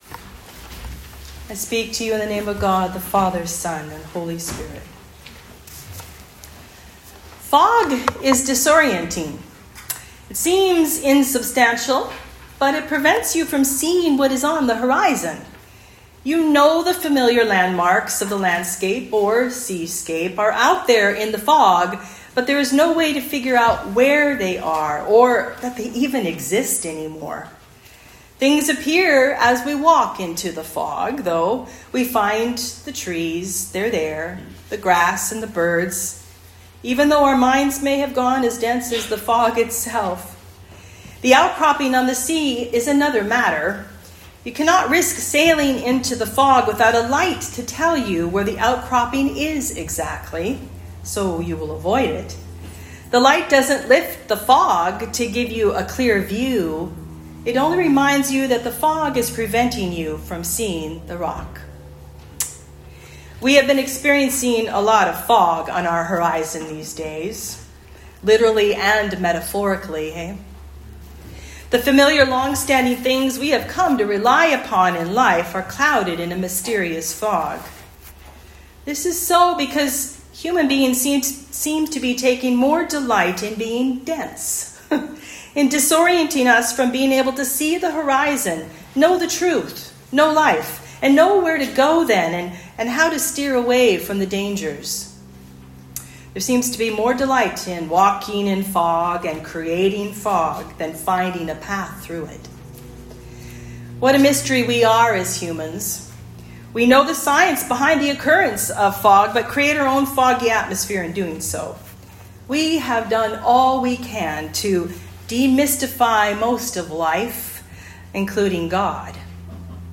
Sermons | Holy Trinity North Saanich Anglican Church